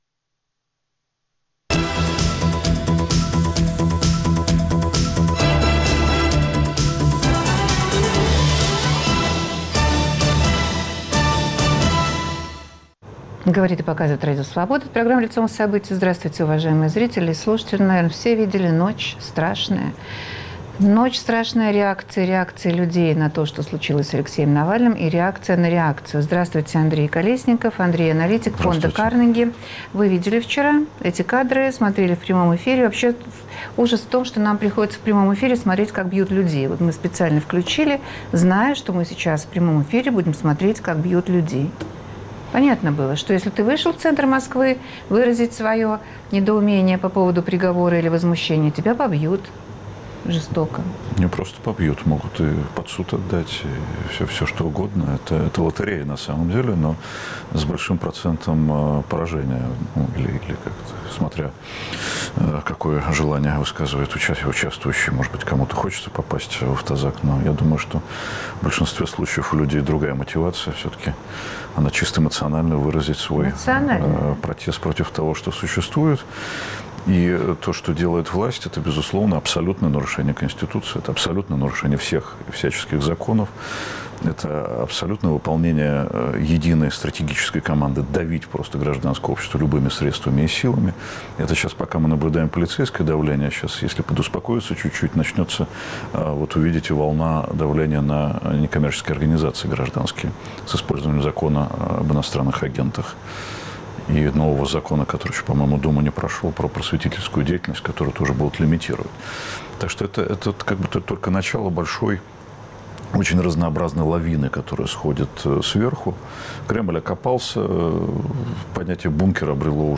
В передаче участвуют политики, аналитики и журналисты из Москвы, Минска и Санкт-Петербурга.